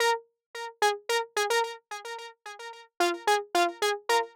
03 Solo Synth PT1.wav